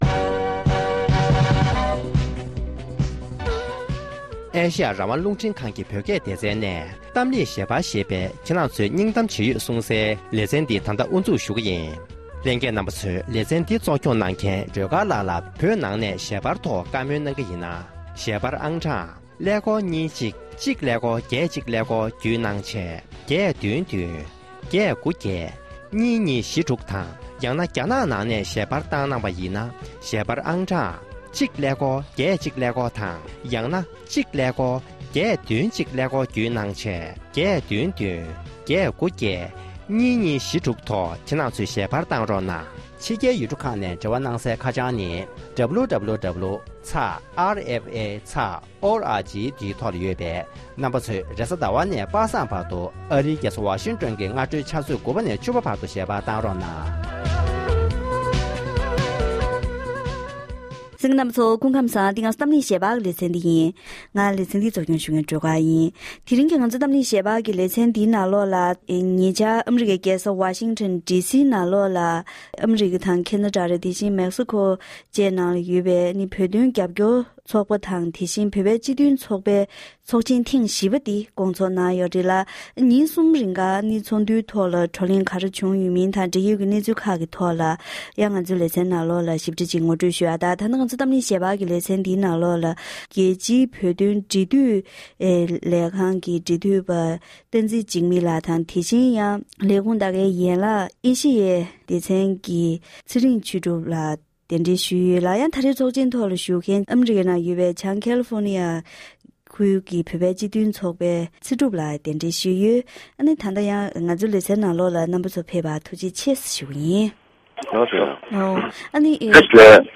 འབྲེལ་ཡོད་མི་སྣར་གླེང་མོལ་ཞུས་པར་གསན་རོགས